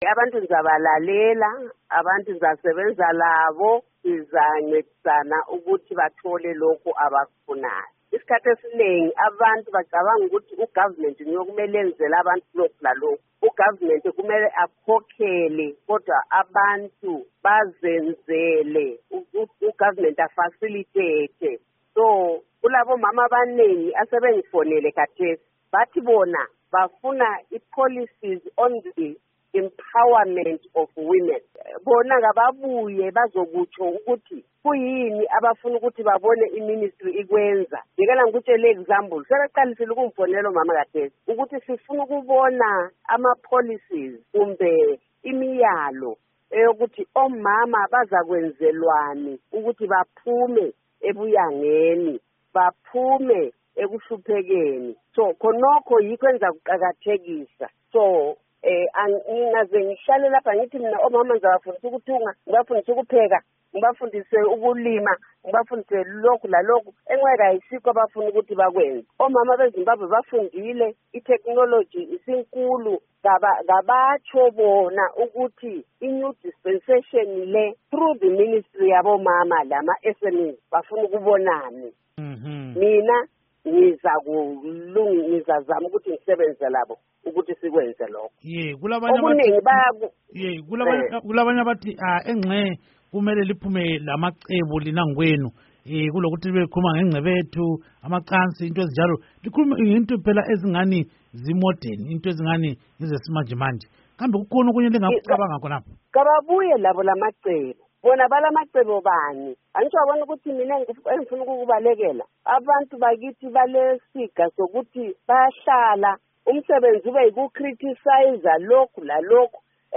Ingxoxo Esiyenze LoNkosikazi Sithembiso Nyoni